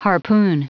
Prononciation du mot harpoon en anglais (fichier audio)
Prononciation du mot : harpoon